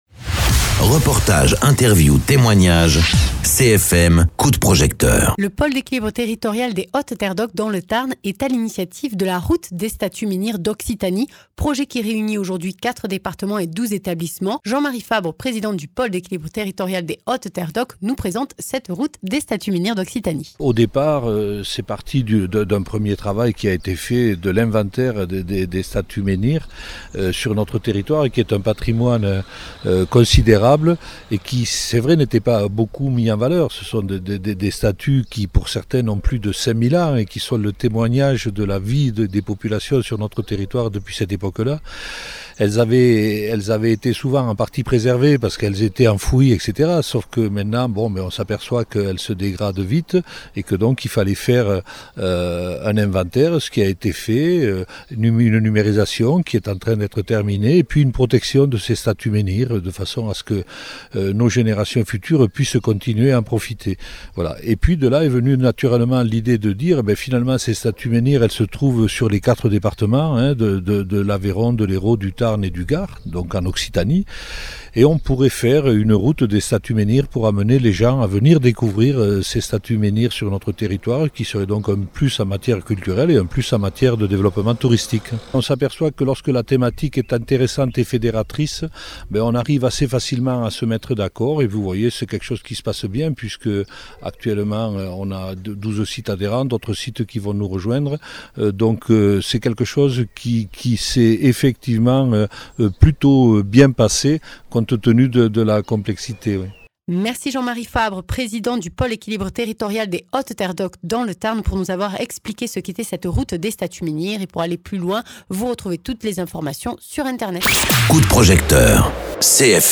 Interviews
Invité(s) : Jean-Marie Fabre, président du pôle d’équilibre territorial des Hautes Terres d’oc dans le Tarn